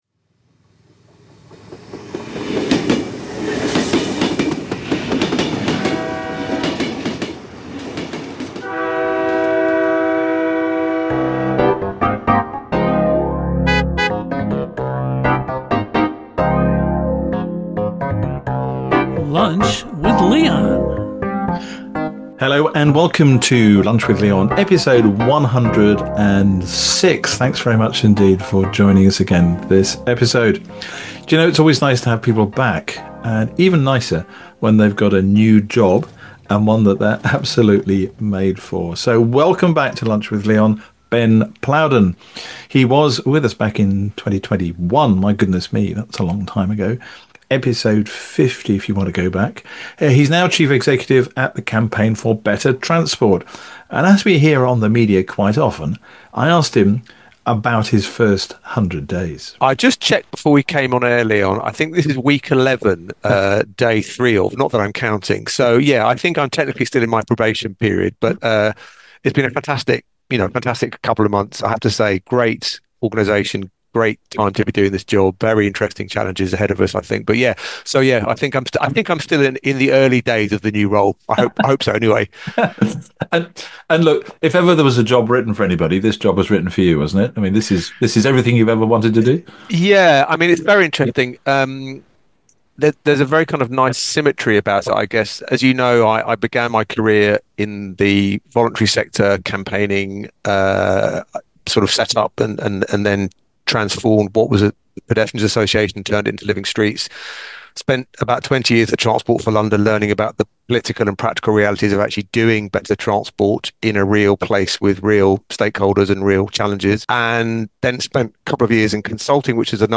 This lively conversation covers various hot topics in the transport industry - the potential softening of the green agenda, rail nationalisation and rail fare debates, the importance of regional connectivity and how to address car dependency.